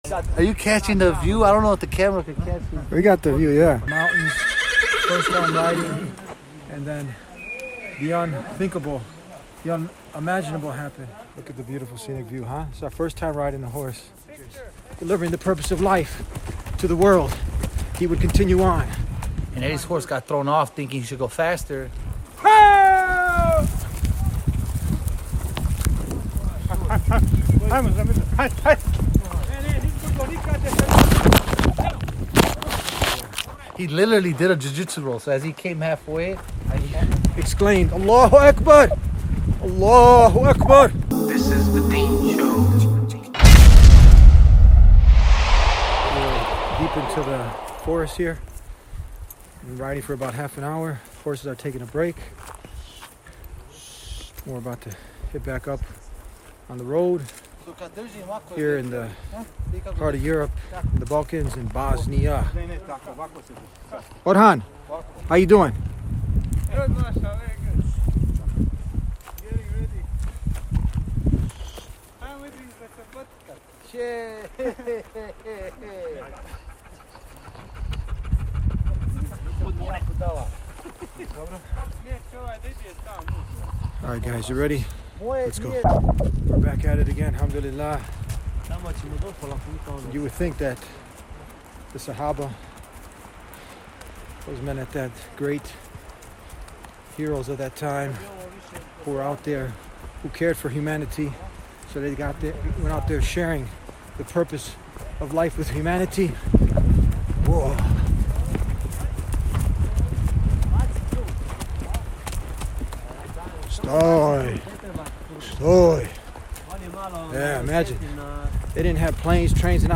Deep in the mountains of Bosnia, TheDeenShow crew embarked on an unforgettable horseback riding adventure through breathtaking alpine forests and mountain trails. What started as a first-time horse riding experience turned into a powerful reminder about life, perseverance, and the legacy of the great companions of the Prophet Muhammad (peace be upon him) who traveled on horseback for months to deliver the message of Islam to the world.